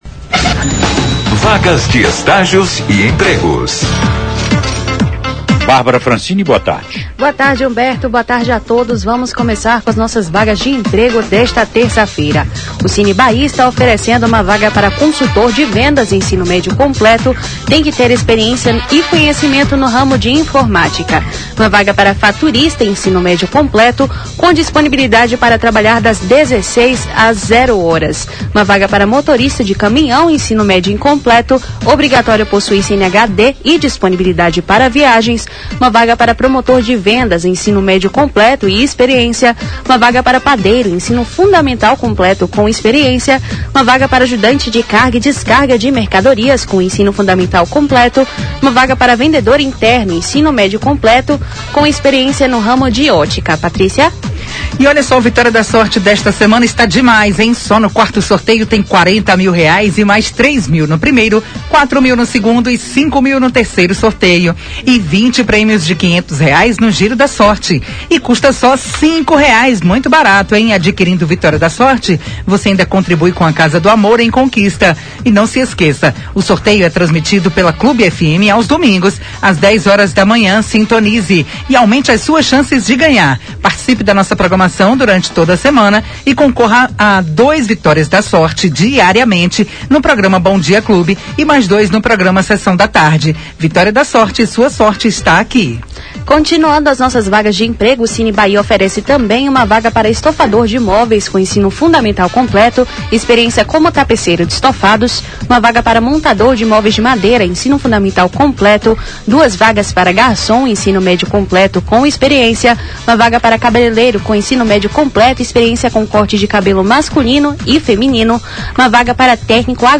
Na sonora, a jovem traz todos os destaques de empregos e estágios em Vitória da Conquista.